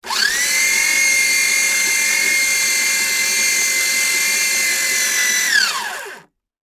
Paper Shredder
Paper shredder destroys documents.